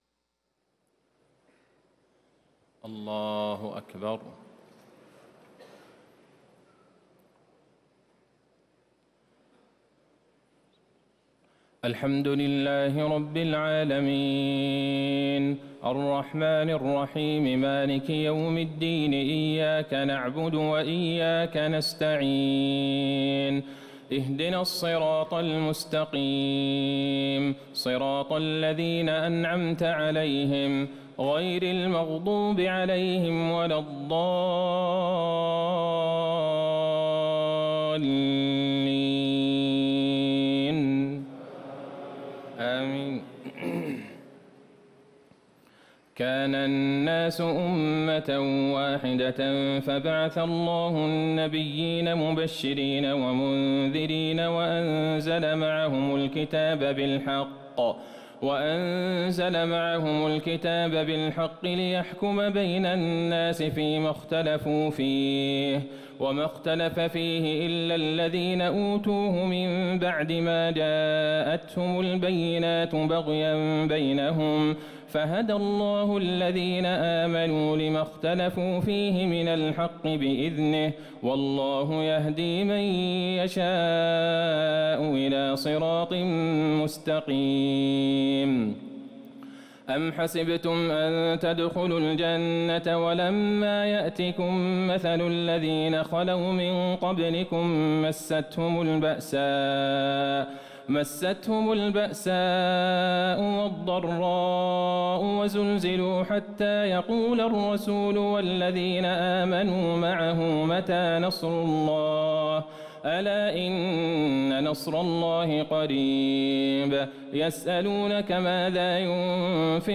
تهجد ليلة 22 رمضان 1438هـ من سورة البقرة (213-253) Tahajjud 22 st night Ramadan 1438H from Surah Al-Baqara > تراويح الحرم النبوي عام 1438 🕌 > التراويح - تلاوات الحرمين